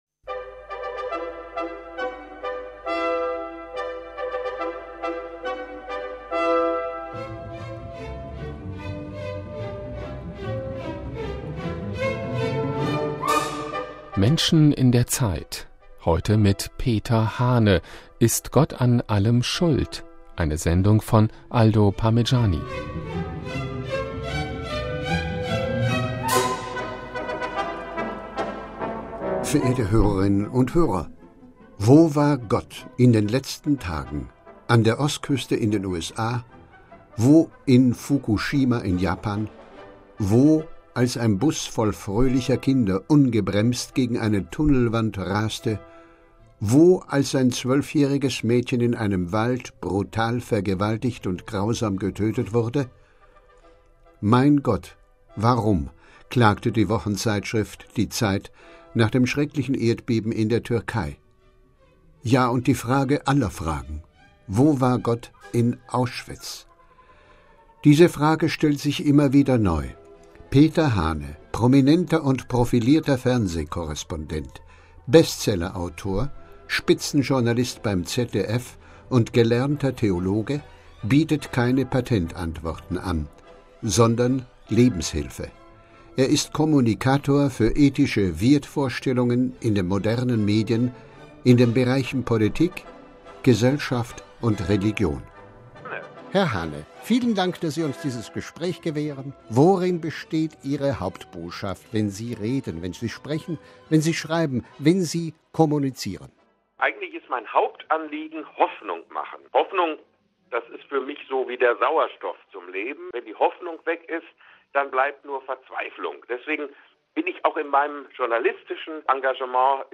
Peter Hahne, prominenter und profilierter Fernseh-Korrespondent, Bestseller-Autor, Spitzen-Journalist beim ZDF und gelernter Theologe bietet keine Patentantworten an, sondern Lebenshilfe.